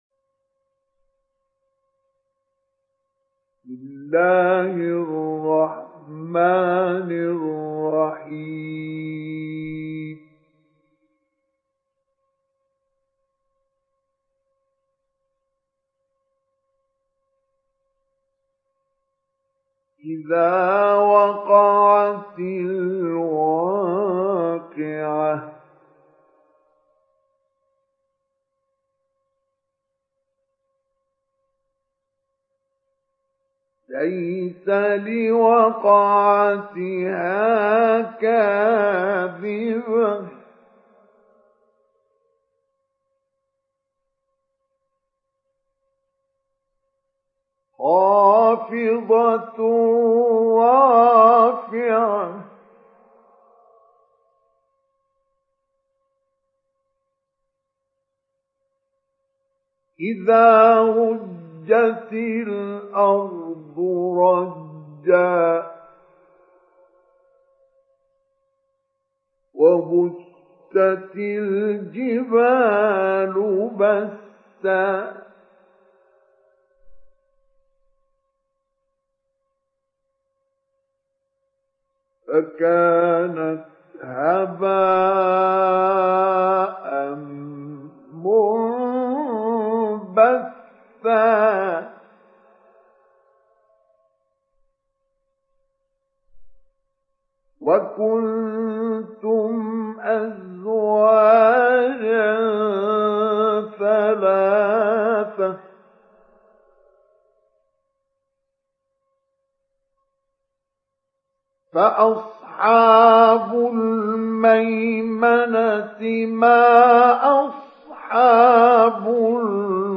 Download Surat Al Waqiah Mustafa Ismail Mujawwad